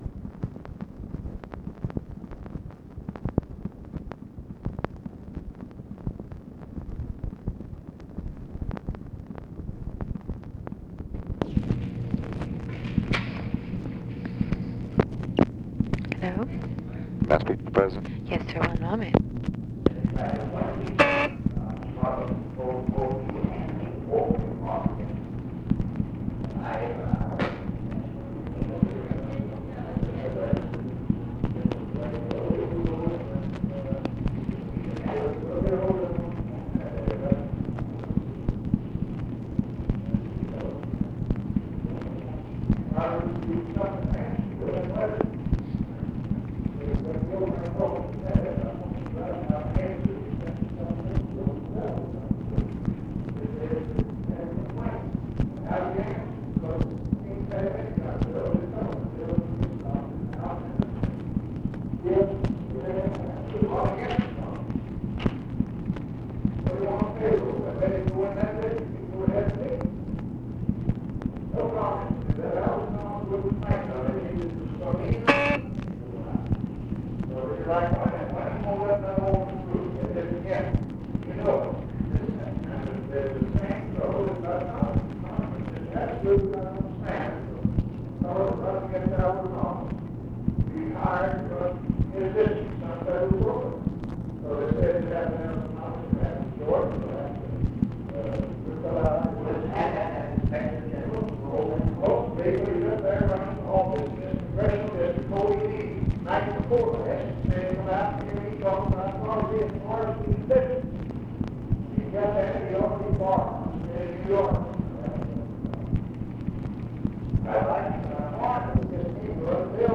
Conversation with DEAN RUSK and OFFICE CONVERSATION, May 19, 1965
Secret White House Tapes